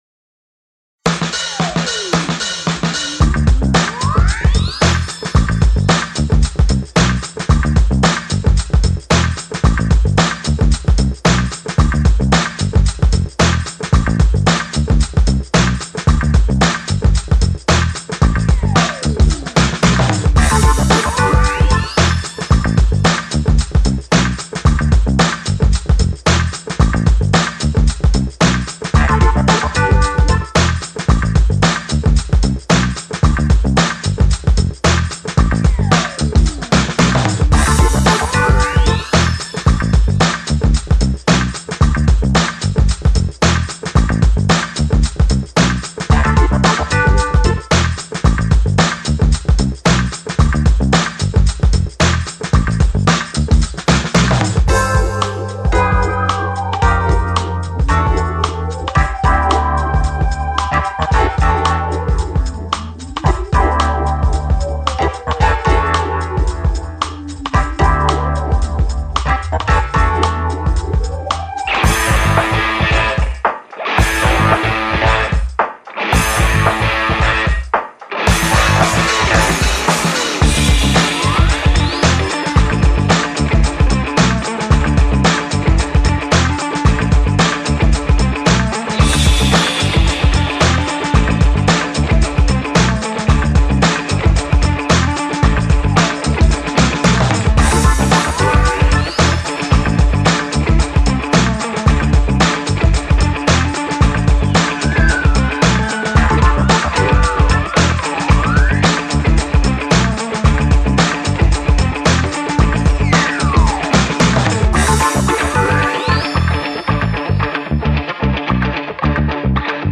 Звук элегантности и стиля на модном подиуме